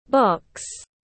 Cái hộp tiếng anh gọi là box, phiên âm tiếng anh đọc là /bɒks/
Để đọc đúng cái hộp trong tiếng anh rất đơn giản, các bạn chỉ cần nghe phát âm chuẩn của từ box rồi nói theo là đọc được ngay.